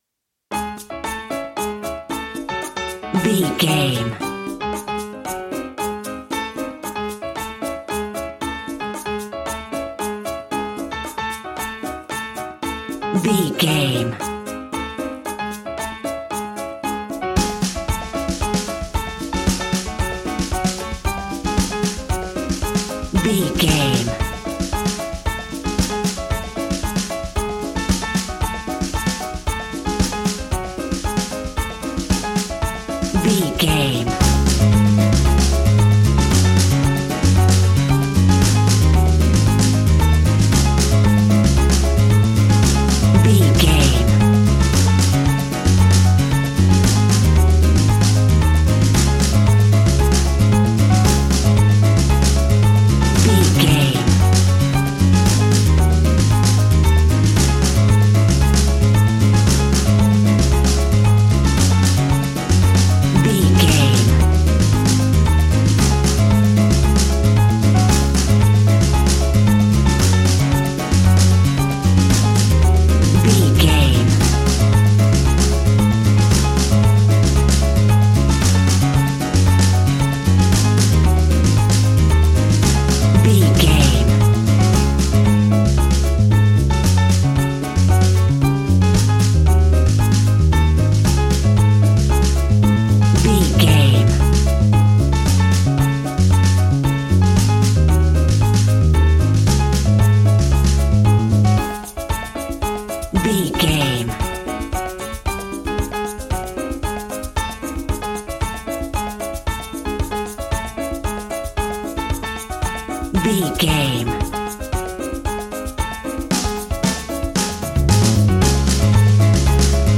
An exotic and colorful piece of Espanic and Latin music.
Aeolian/Minor
C#
flamenco
romantic
maracas
percussion spanish guitar